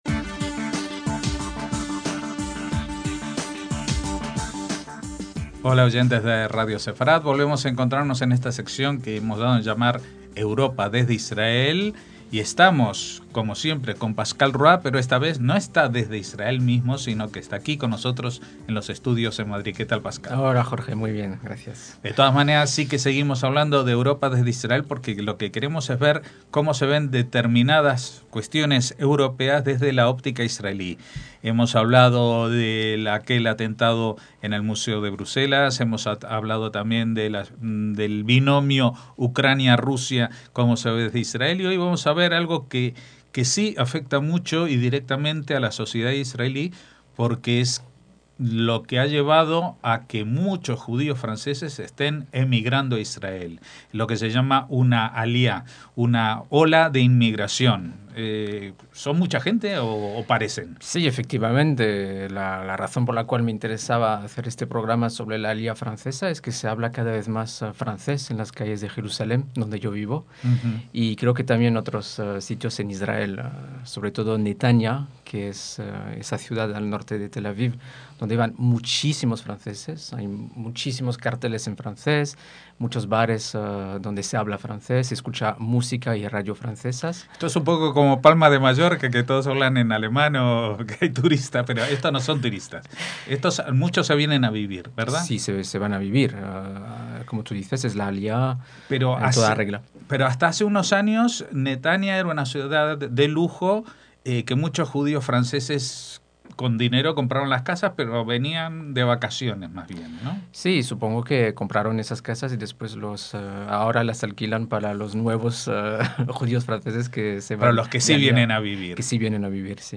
en directo en el estudio para hablarnos del fenómeno inmigratorio más importante de Israel en los últimos años: la llegada de judíos franceses, mayoritariamente originarios de países magrebíes, especialmente Argelia, y de cómo ven al nuevo país y son vistos por los israelíes.